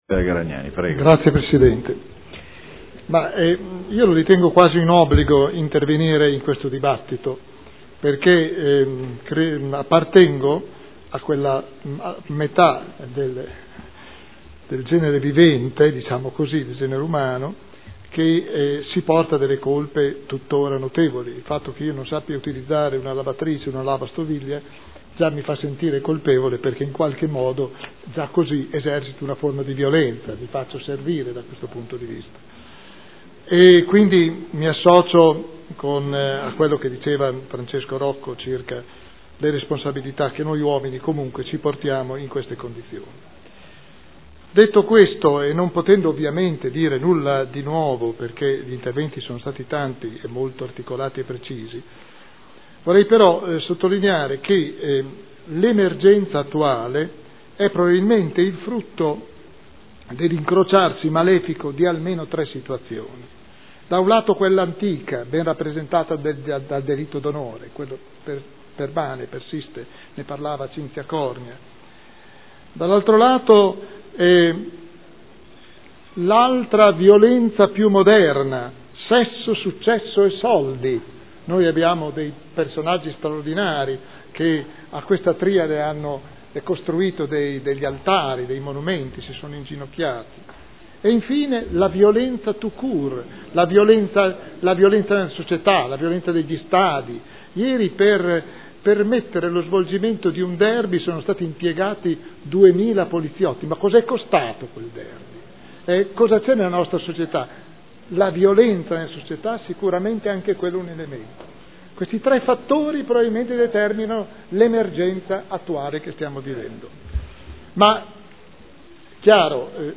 Seduta del 27/05/2013. Dibattito.